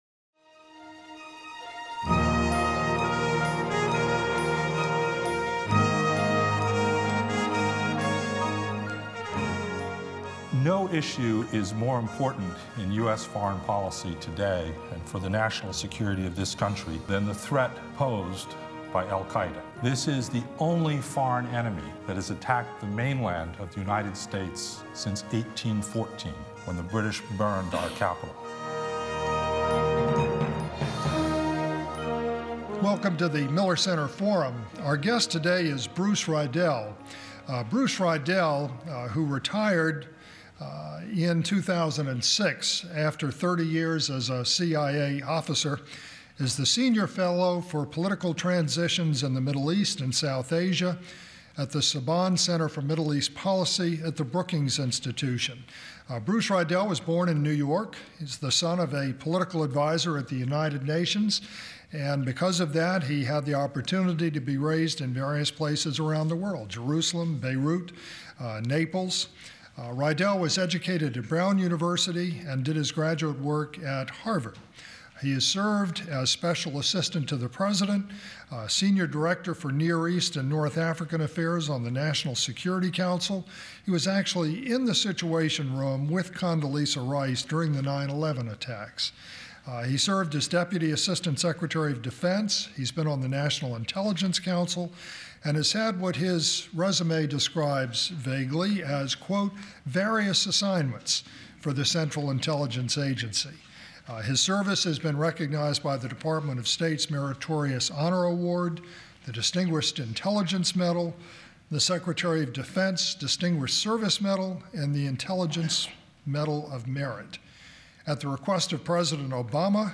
A book signing followed his Forum.